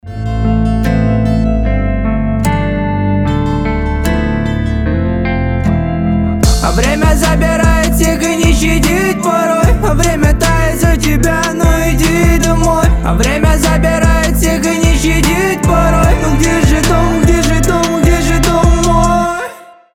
• Качество: 320, Stereo
лирика
пацанские